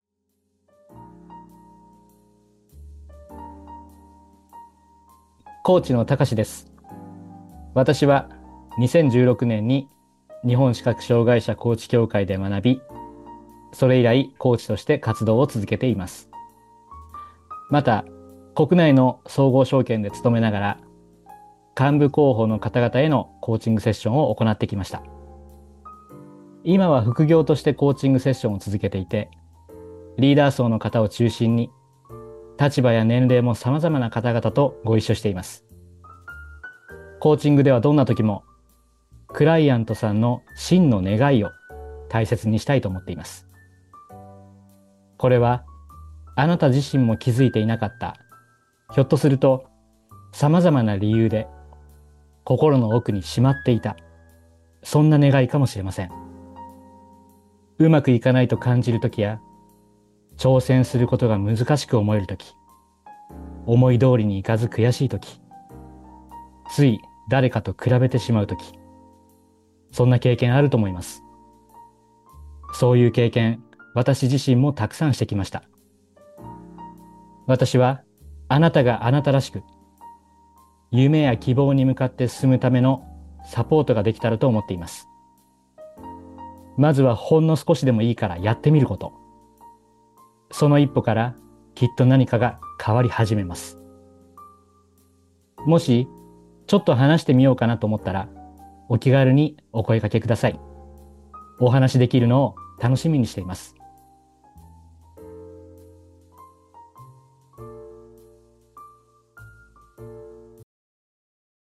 コーチからのメッセージ